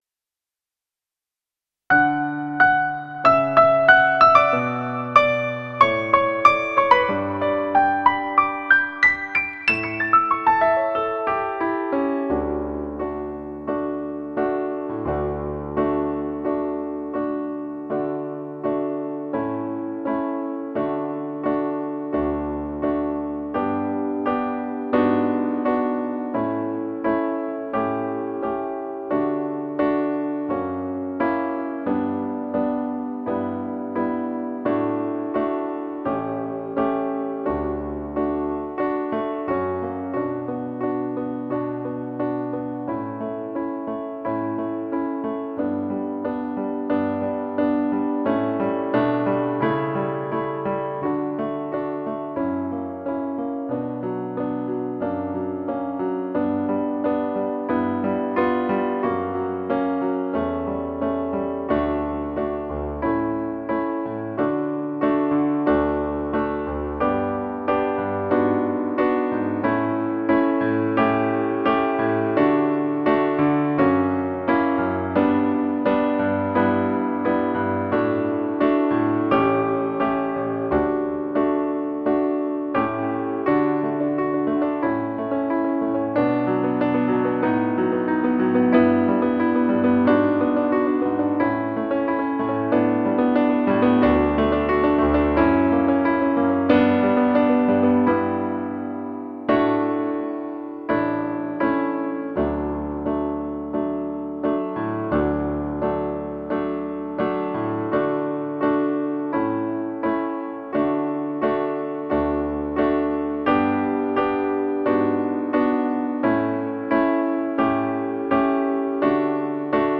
４．カラオケ（ピアノ）Ｖｅｒ